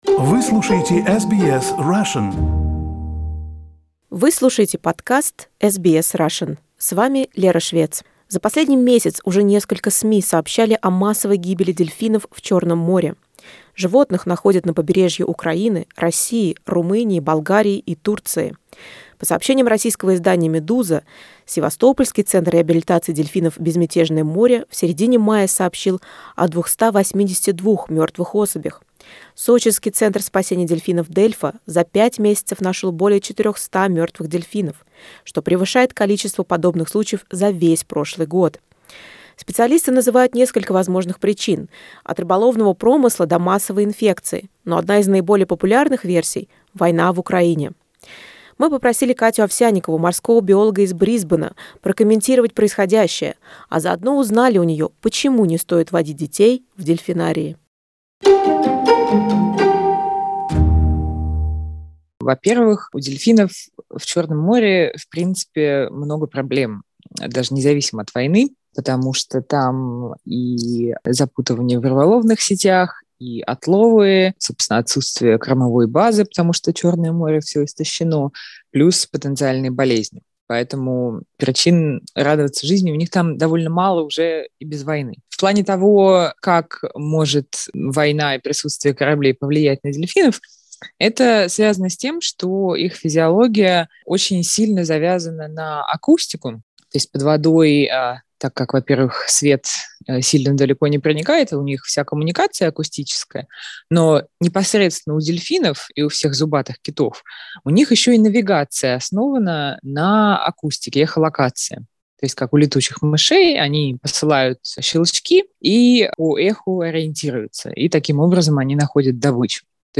прокомментировала в разговоре с SBS Russian последние новости о массовой гибели дельфинов в Черном море и предполагаемую связь происходящего с войной в Украине.